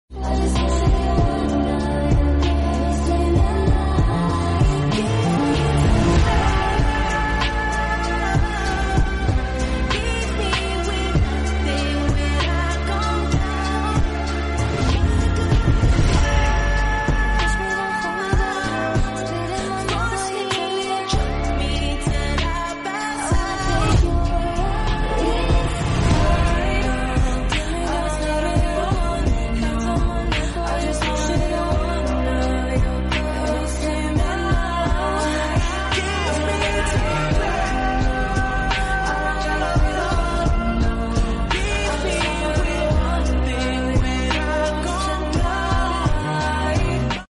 Second before land🛬. Momen pesawat sound effects free download